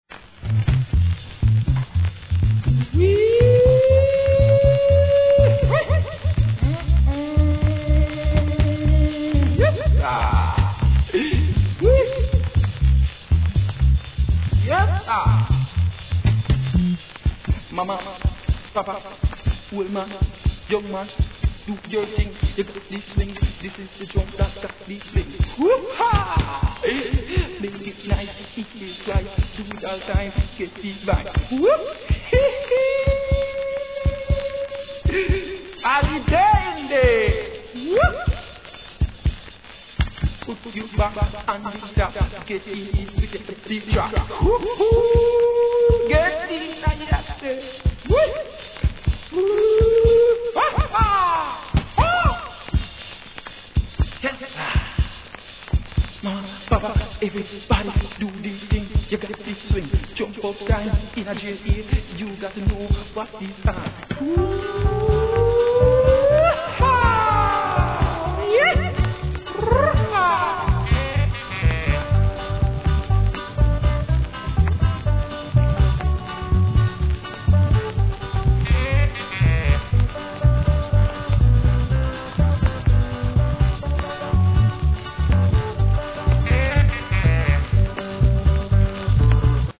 REGGAE
イナタいシャウとがいかした1975年カリプソ作品!!